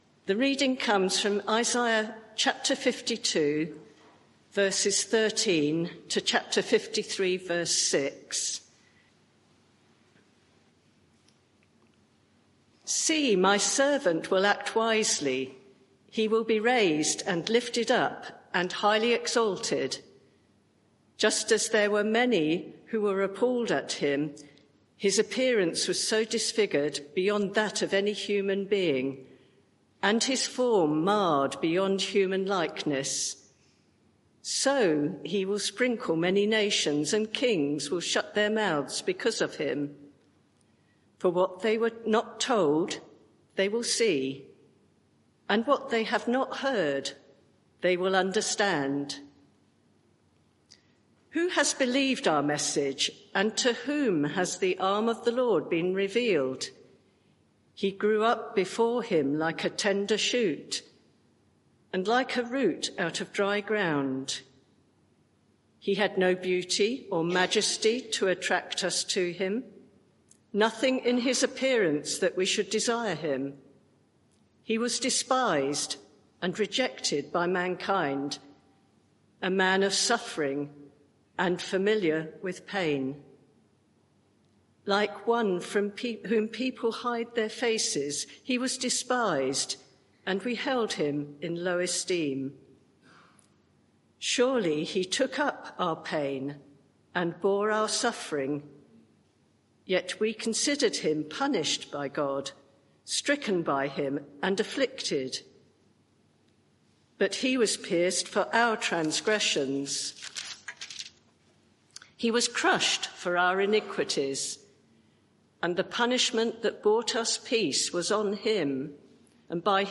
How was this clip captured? Media for 11am Service on Sun 01st Dec 2024 11:00 Speaker